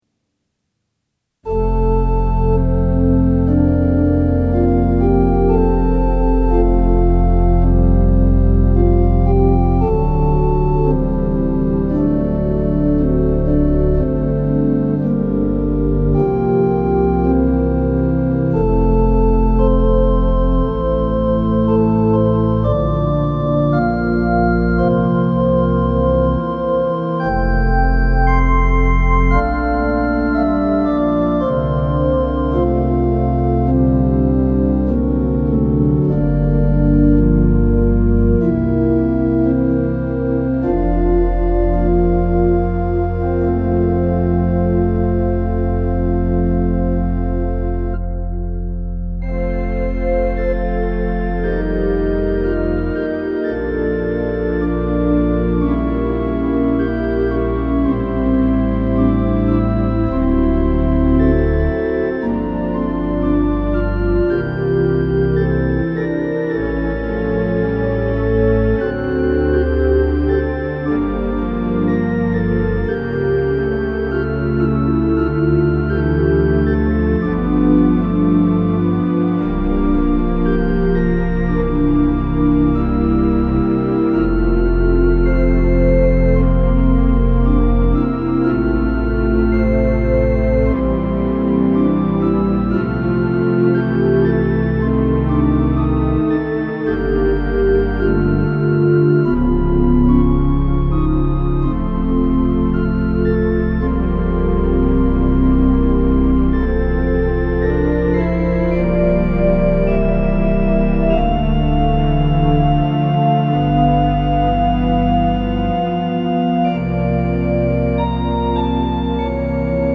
Organ Meditations Audio Gallery
Peaceful works for quiet reflection